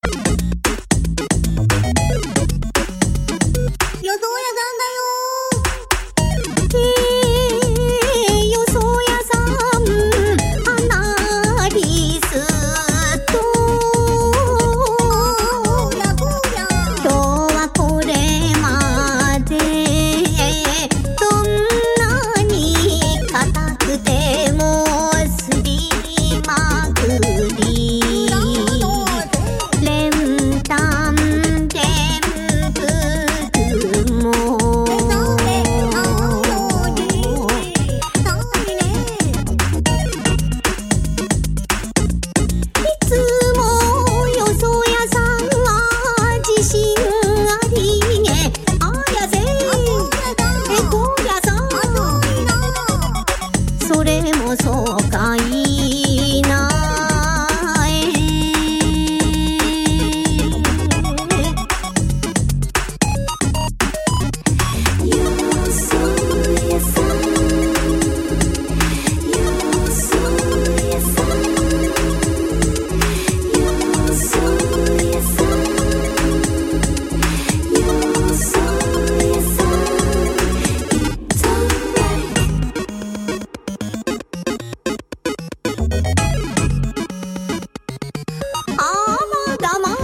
ゲーム音楽ミーツ日本民謡